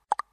Звуки чпок